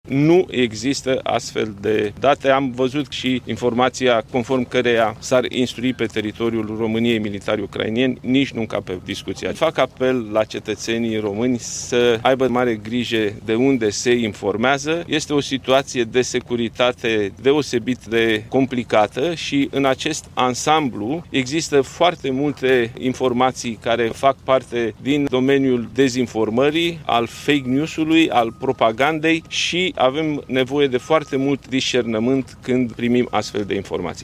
Premierul Nicolae Ciucă: